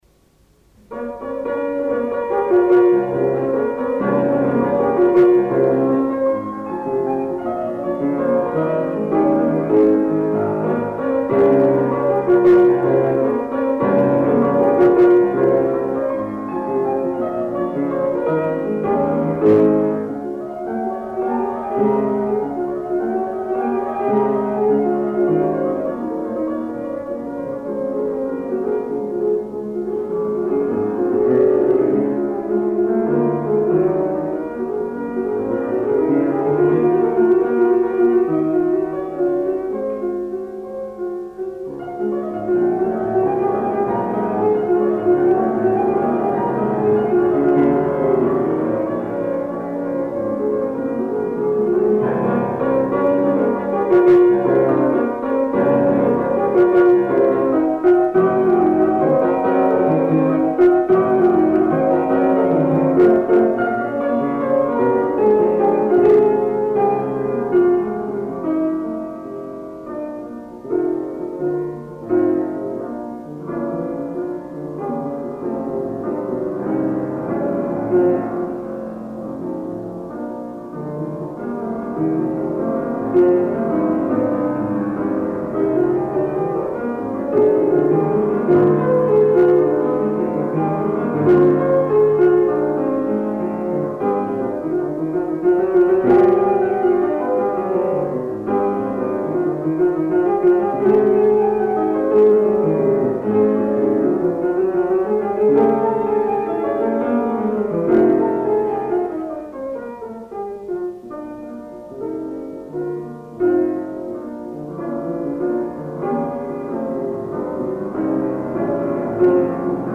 Klavier 2
02_Klavier_2.mp3